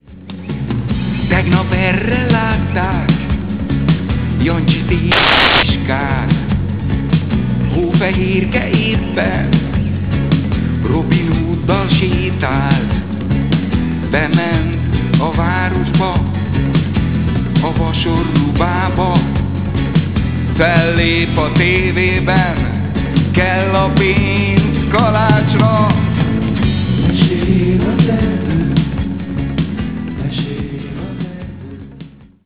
akusztikus és elektromos gitár
basszusgitár, fuvola, szintetizátor, zongora, ének, vokál
dobok, ritmushangszerek
tangóharmónika
altszaxofon
hegedű
szájharmónika
ütőhangszerek